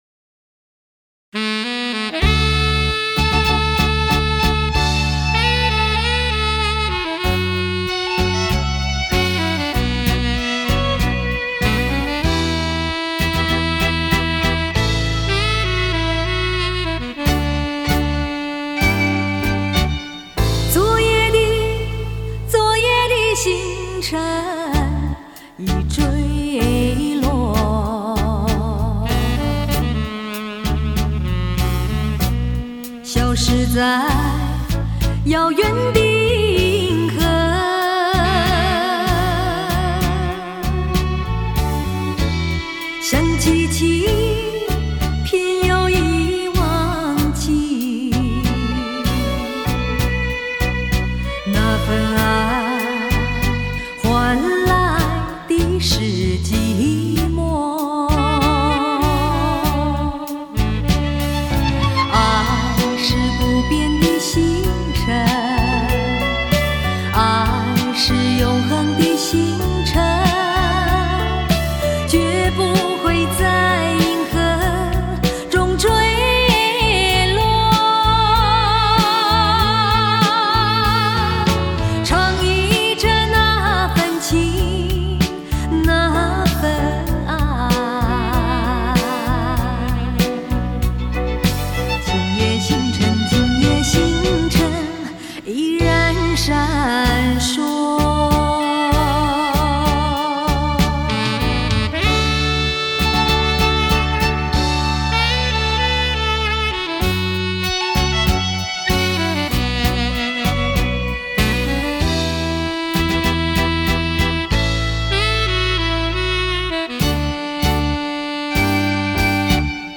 样本格式    : 44.100 Hz; 16 Bit; 立体声